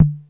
Vintage Tom 03.wav